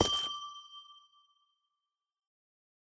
Minecraft Version Minecraft Version latest Latest Release | Latest Snapshot latest / assets / minecraft / sounds / block / amethyst / step6.ogg Compare With Compare With Latest Release | Latest Snapshot
step6.ogg